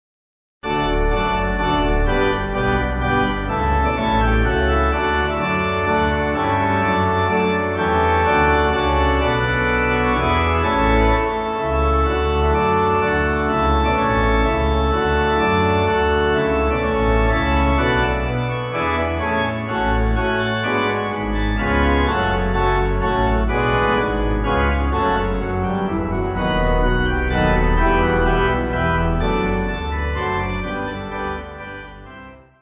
Organ
Easy Listening   D